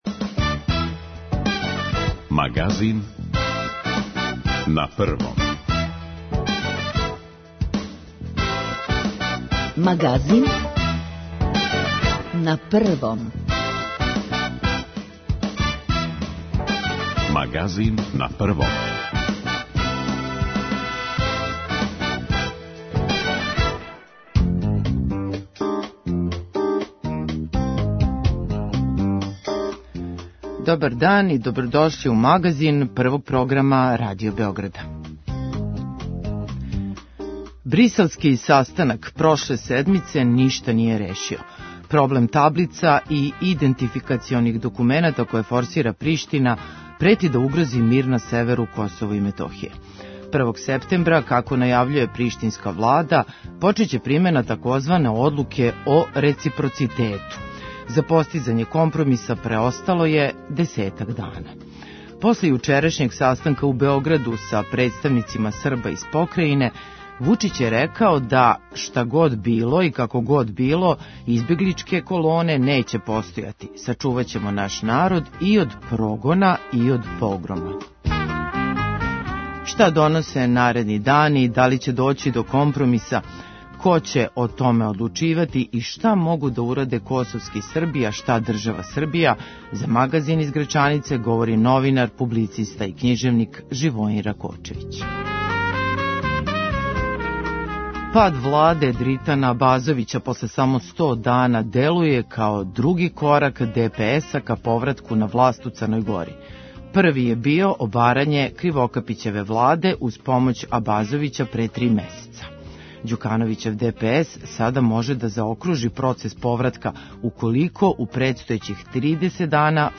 Аудио подкаст Радио Београд 1